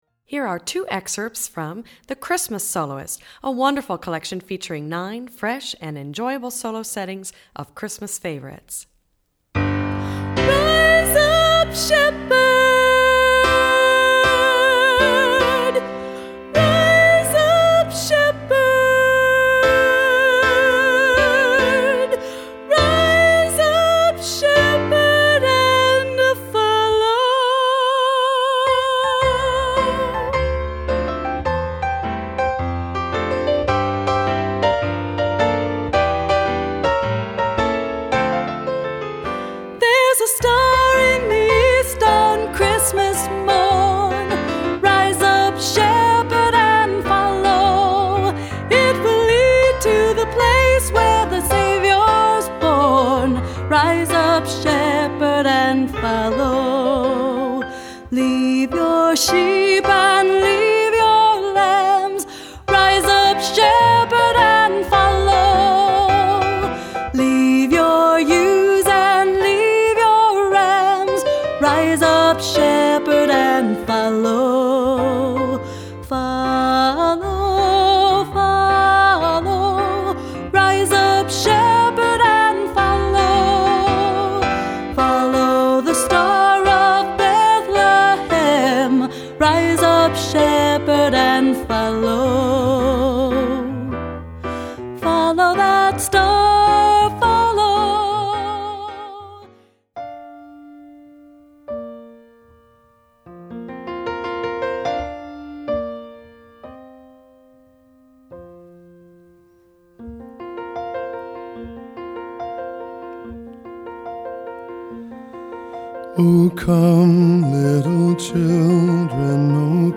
Voicing: Medium-High Voice